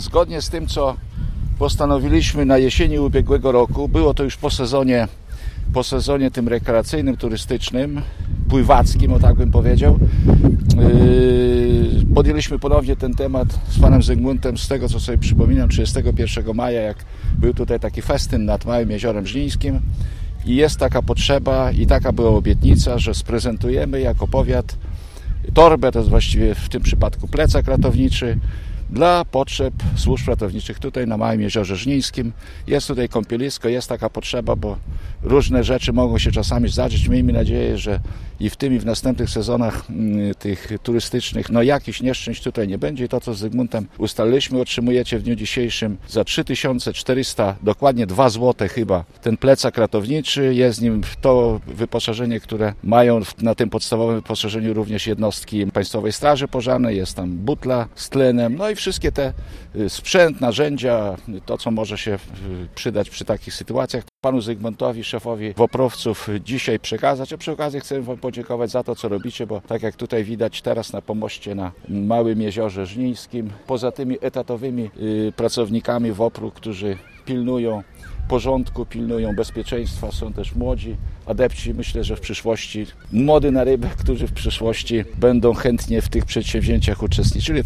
Działania WOPR-owców wspiera Starostwo Powiatowe w Żninie. Dziś urząd przekazał specjalistyczny sprzęt, który pomoże ratownikom podczas pracy w okresie letnim, mówi starosta żniński Zbigniew Jaszczuk.